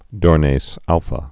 (dôrnās ălfə, -nāz)